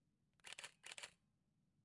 相机快门1/250秒（快速）。
描述：我的尼康D7100快门的声音附有70300mm镜头。使用Blue Yeti Pro录制，摄像机与麦克风距离不同。
标签： 快门声 单反相机快门 相机 尼康 单反相机 快门点击 相机快门 尼康快门 数码单反相机 快门
声道立体声